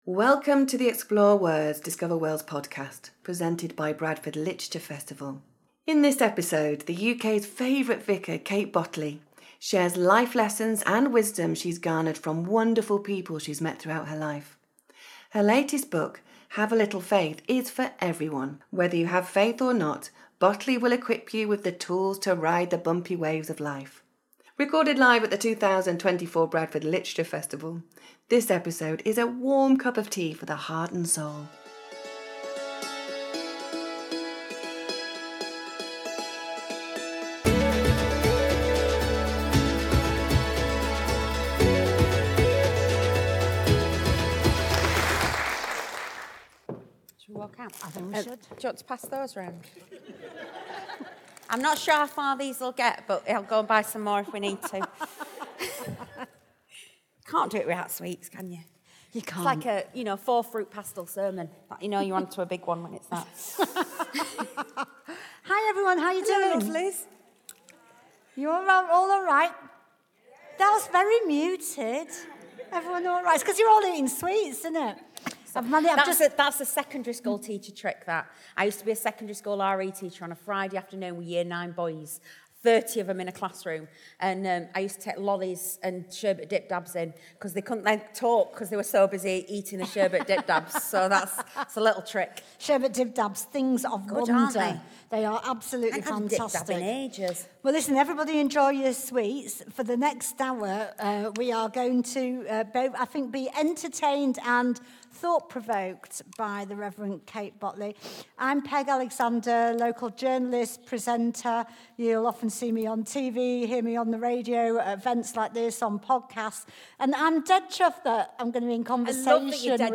Kate Bottley in Conversation (; 01 Jan 2025) | Padverb
The UK’s favourite vicar, Kate Bottley, returned to Bradford to share some life lessons.